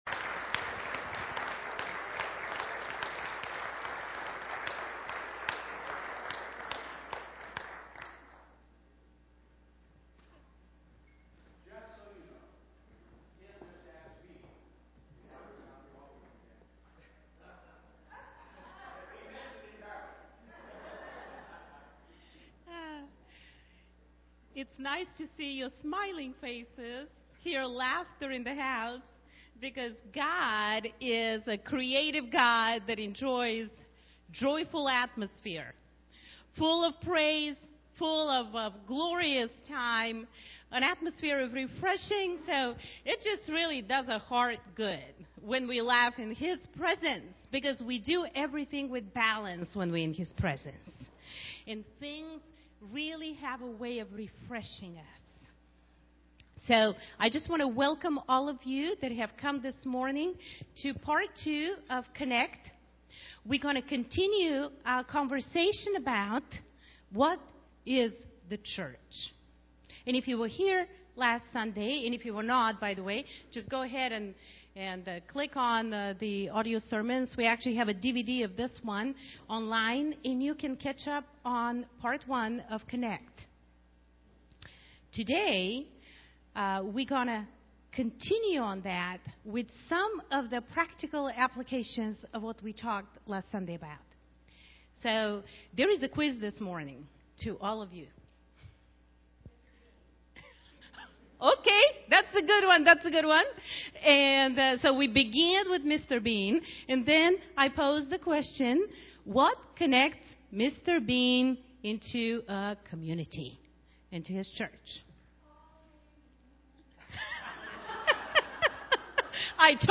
This is the second part of a multi-part sermon series.